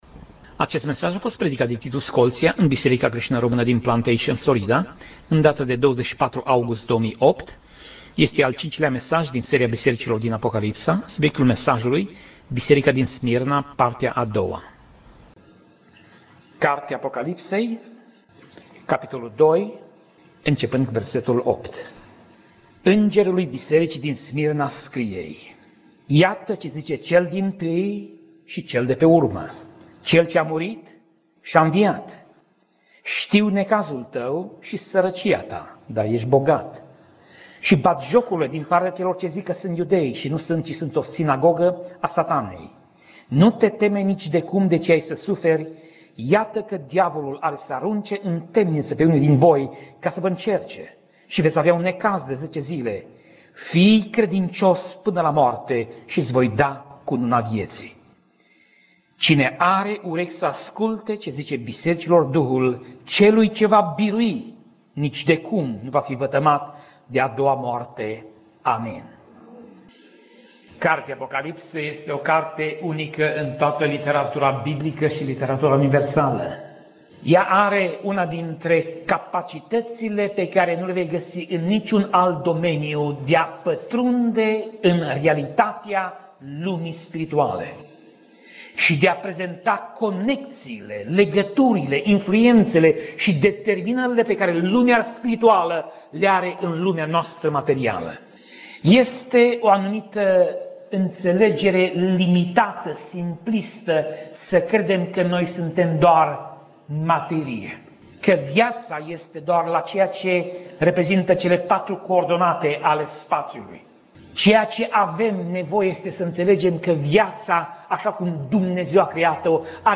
Tip Mesaj: Predica Serie: Cele Sapte Biserici din Apocalipsa